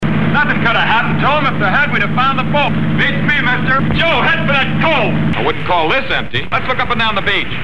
Die Sounddatei gibt übrigens Lex' kompletten Text in diesem Film wieder.
Lex Barker: Küstenwachmann
Hörprobe mit Lex' eigener Stimme